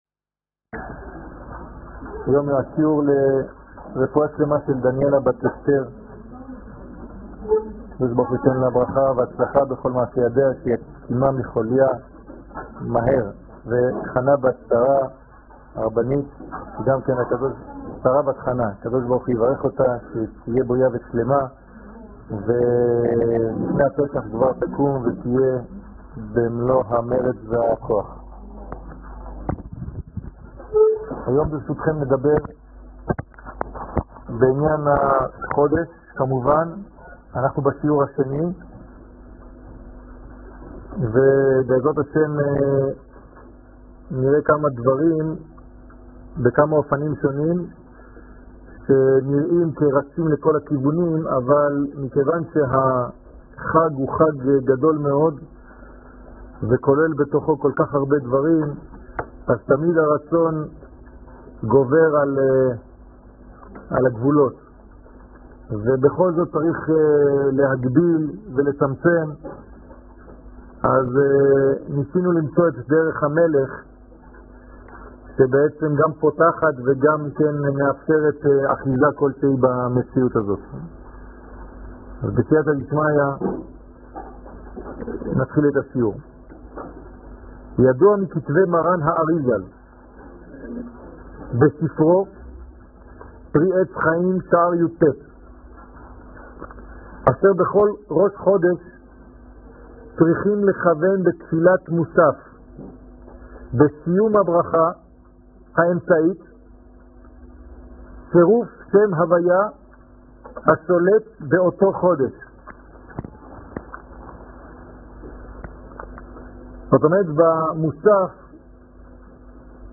שיעורים
שיעורים קצרים